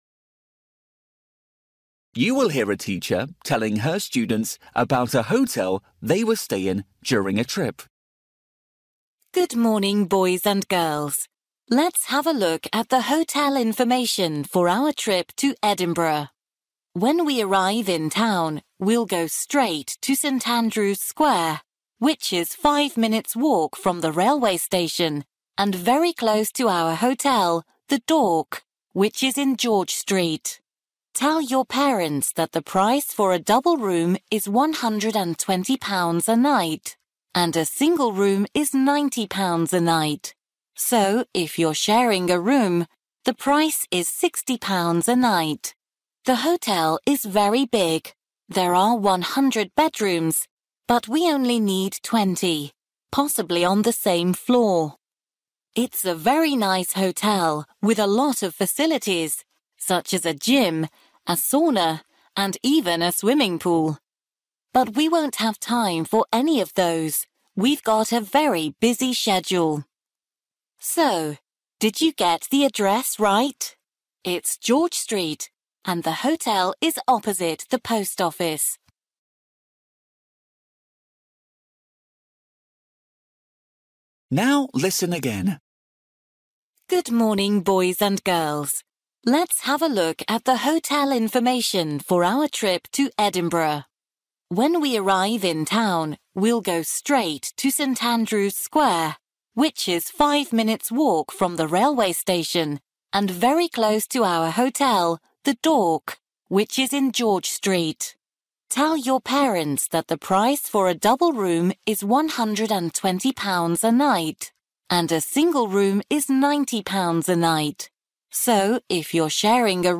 You will hear a teacher telling students about a hotel they will stay in during a trip.